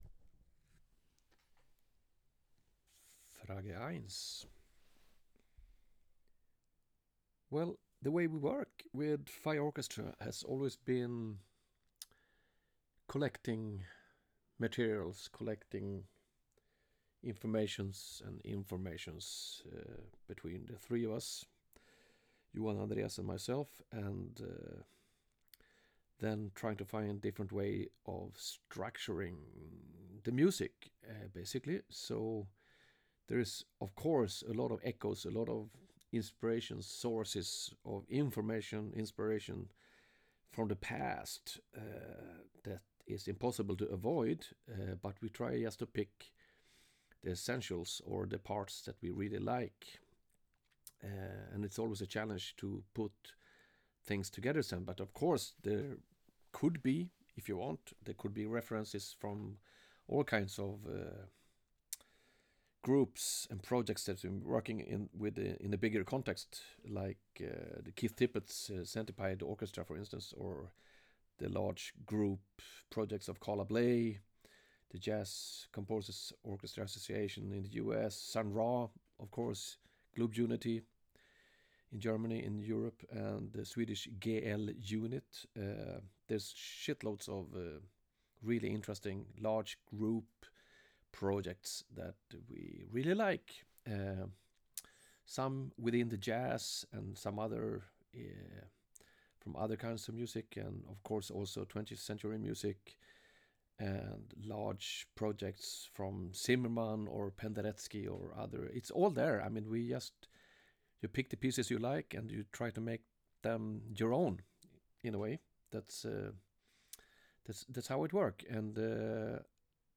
Thanks, Mats, for doing this interview „from a distance“.